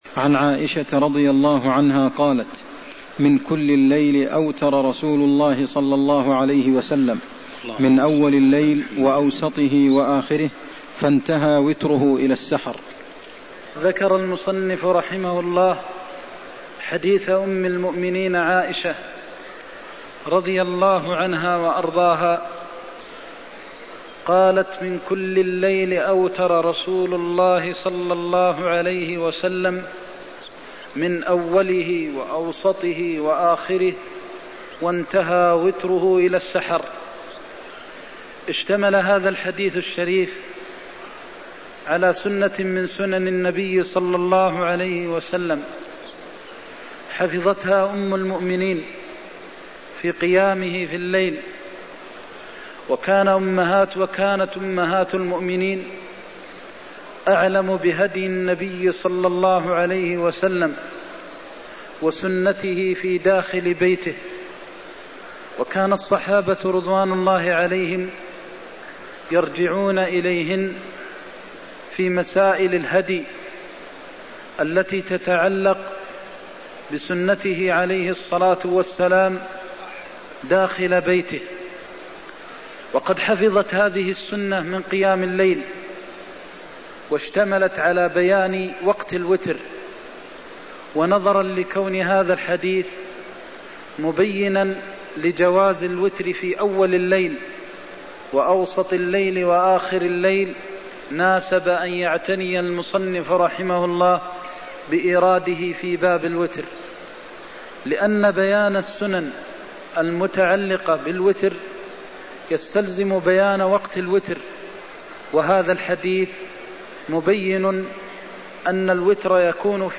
المكان: المسجد النبوي الشيخ: فضيلة الشيخ د. محمد بن محمد المختار فضيلة الشيخ د. محمد بن محمد المختار صفة قيامه وتهجده صلى الله عليه وسلم من الليل (120)